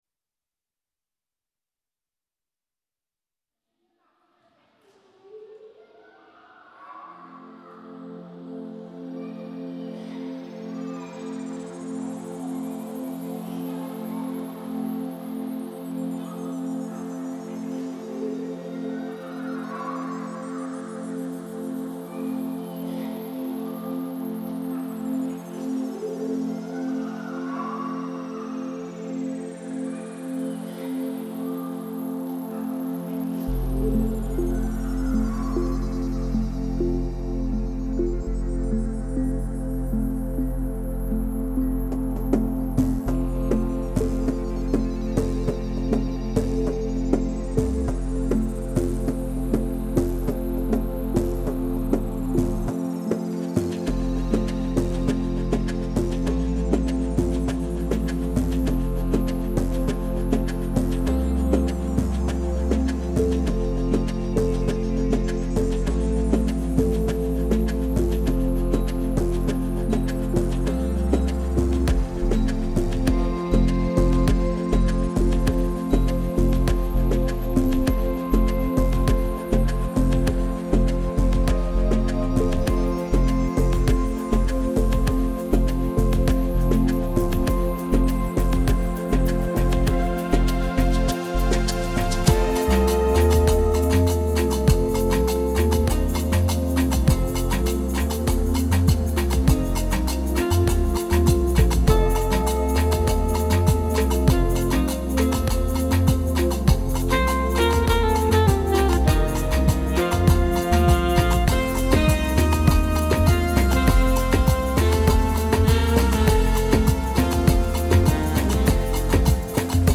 Epoque :  Musique d'aujourd'hui
Style :  Avec accompagnement
Enregistrement instrumental